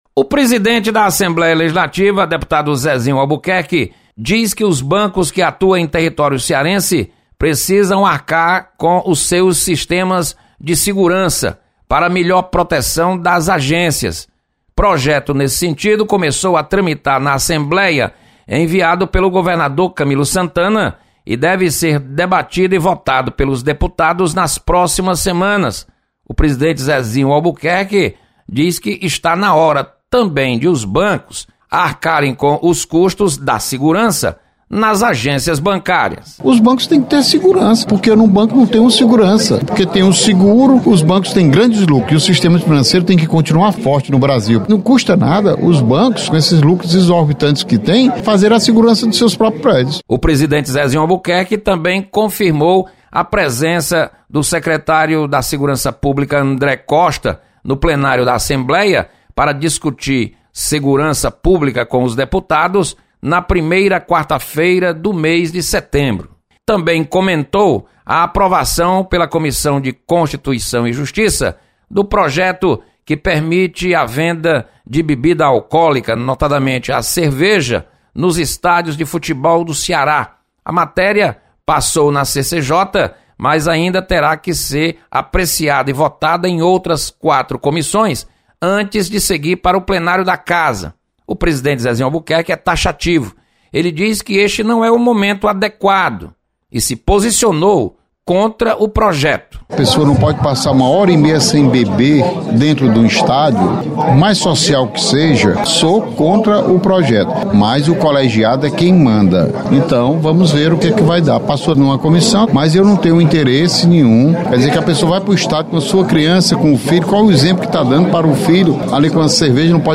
Deputado Zezinho Albuquerque fala sobre a falta de segurança nas agências bancárias e é contrário à venda de bebidas alcoolicas nos estádios.